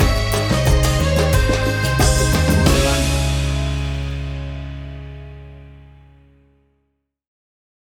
Tempo (BPM): 90